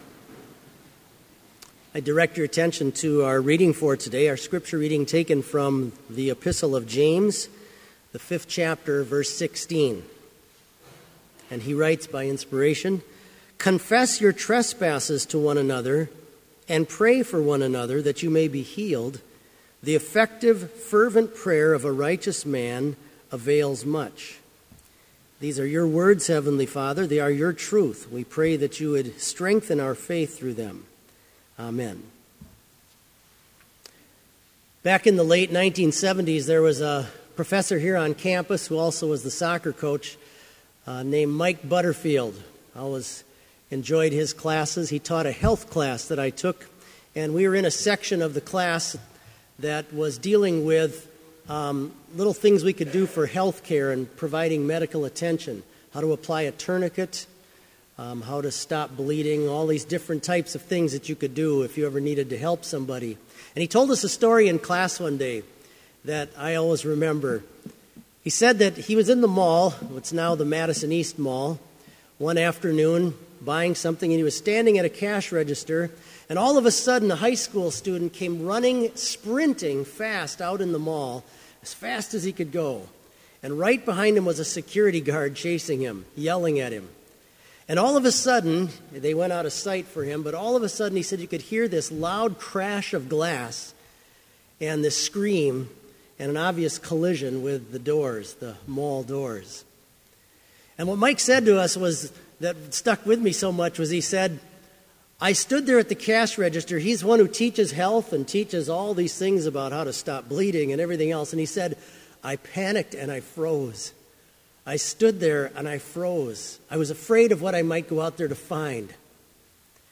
Complete Service
• Devotion
This Chapel Service was held in Trinity Chapel at Bethany Lutheran College on Friday, October 9, 2015, at 10 a.m. Page and hymn numbers are from the Evangelical Lutheran Hymnary.